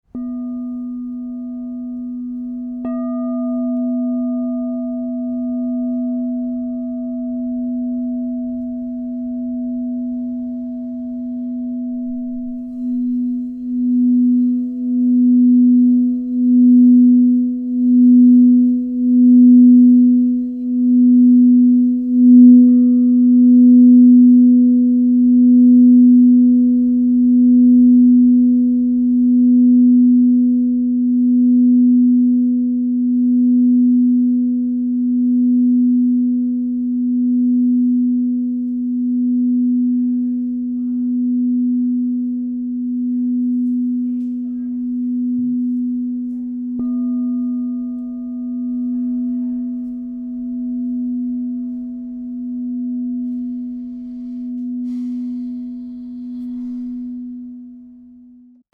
Smoky Quartz 9" B 0 - Divine Sound